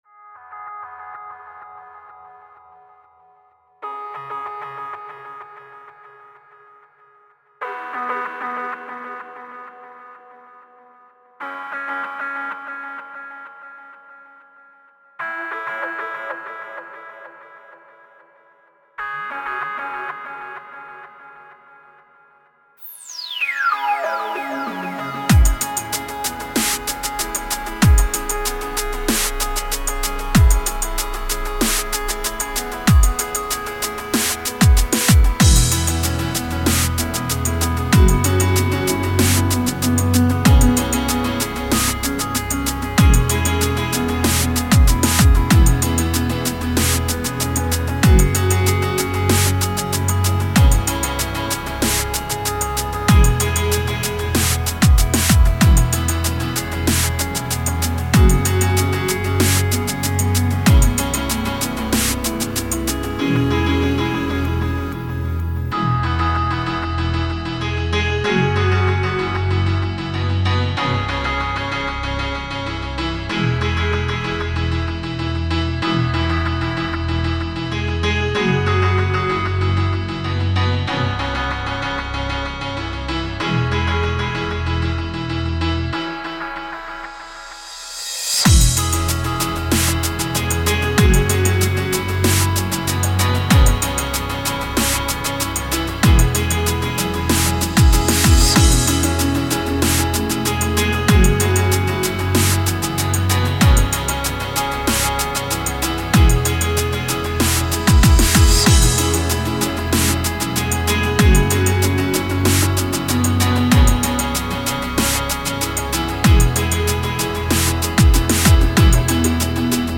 Very chill track.. 95BPM I found an interesting trick with the cutoff for the background melody that gave it a unique wah-owl effect.. and somehow everything began to flow together in harmony with the Chord =P Everything except the drums are stock FLstudio plugins/effects
It was was chill-out and calming.